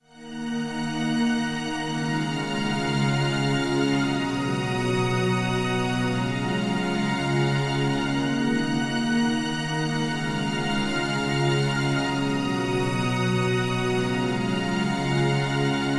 Pizzicato Chill
标签： 120 bpm Rap Loops Strings Loops 697.42 KB wav Key : Unknown
声道立体声